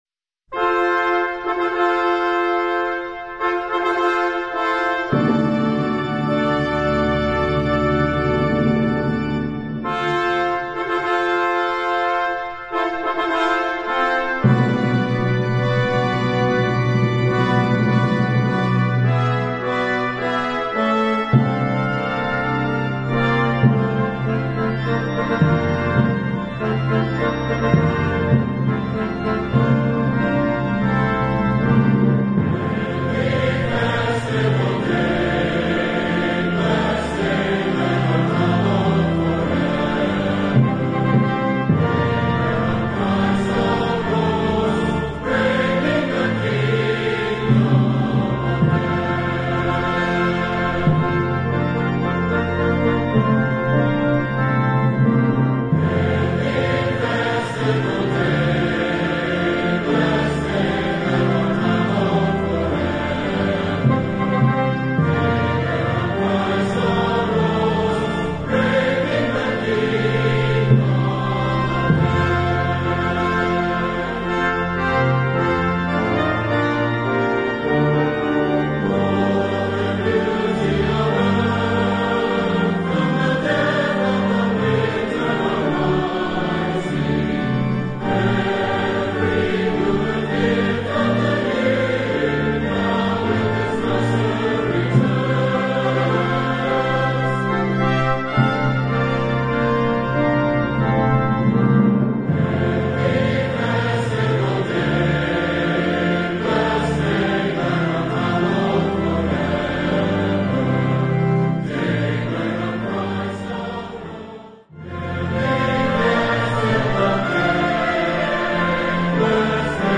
Voicing: SATB and Congregation